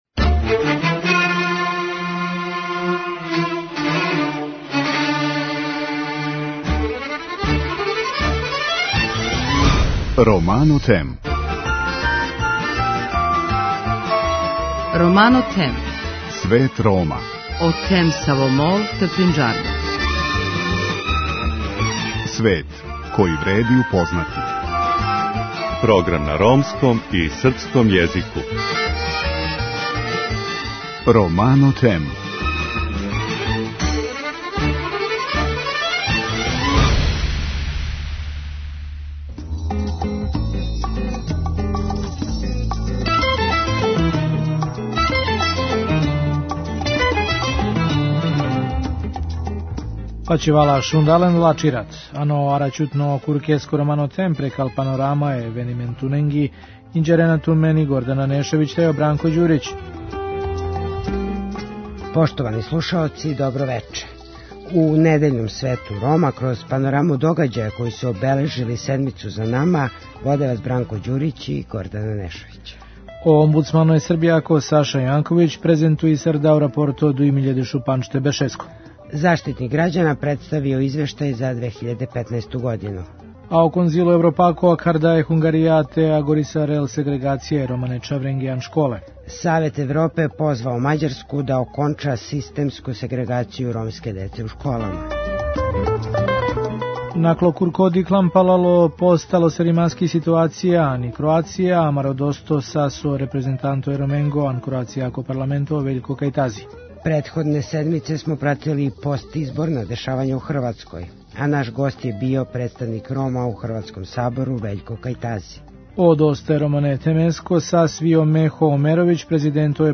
Претходне седнице смо пратили постизборна дешавања у Хрватској, а наш гост је био представник Рома у Хрватском Сабору Вељко Кајтази.